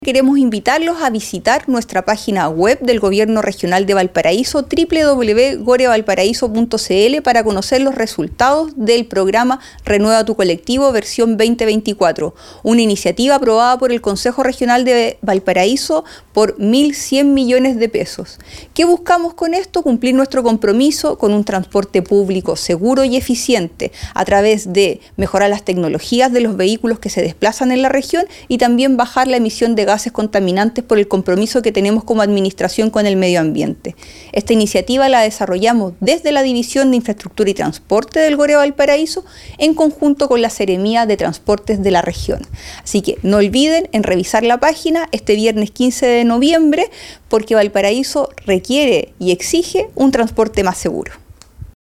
La Gobernadora Regional (S) de Valparaíso, Macarena Pereira, entregó más detalles.